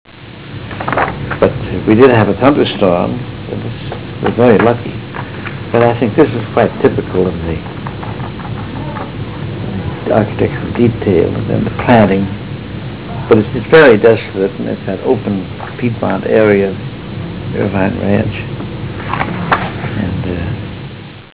171Kb Ulaw Soundfile Hear Ansel Adams discuss this photo: [171Kb Ulaw Soundfile]